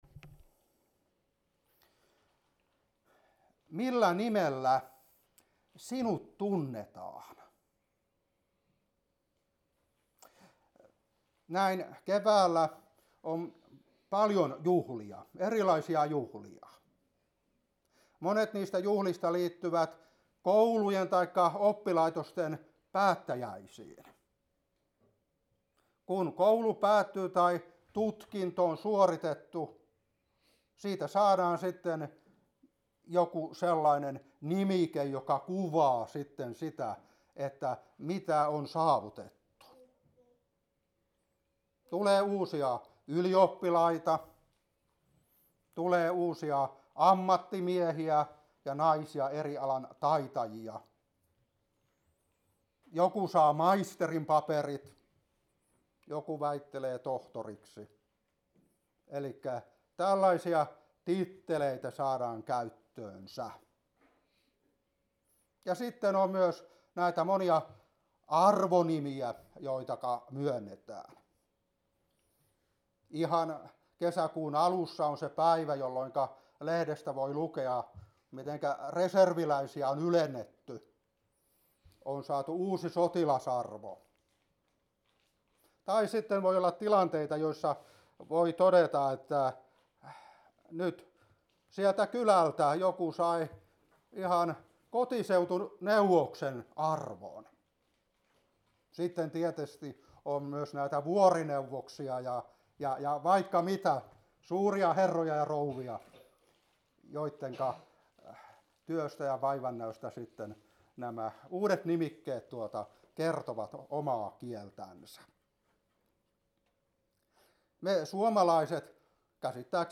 Opetuspuhe 2024-6.